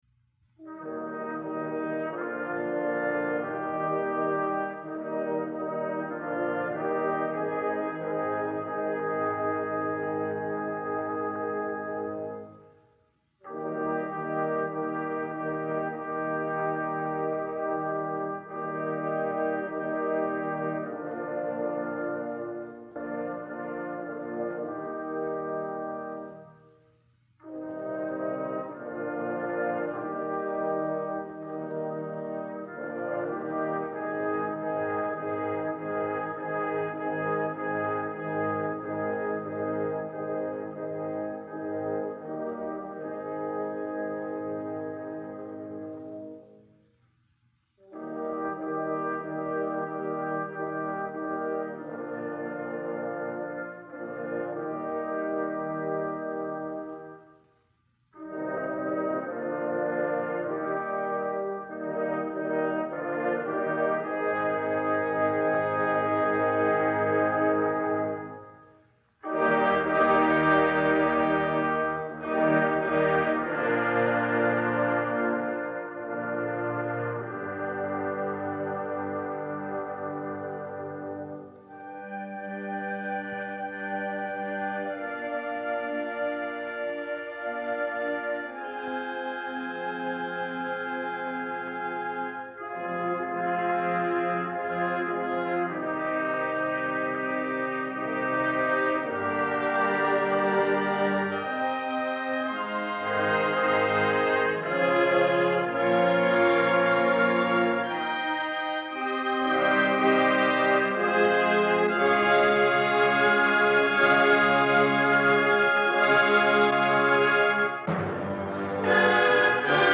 Bethany Nazarene College Band in Concert 1963-1964